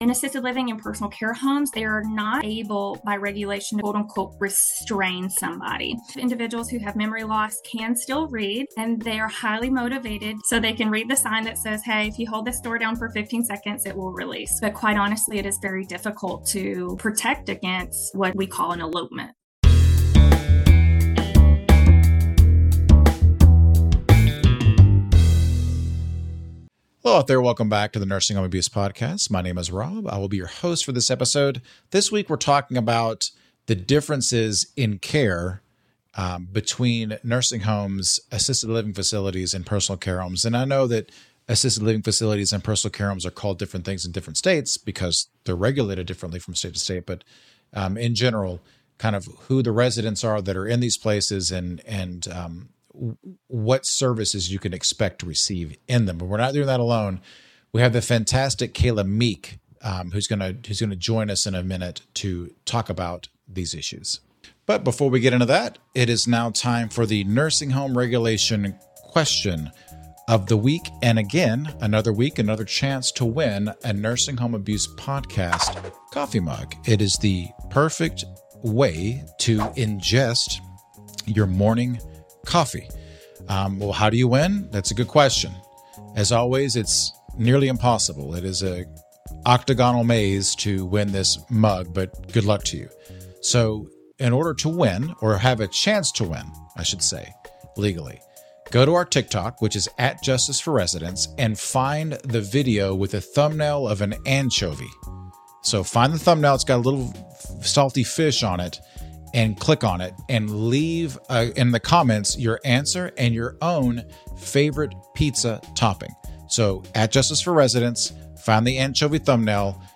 In this week’s episode, nursing home abuse lawyer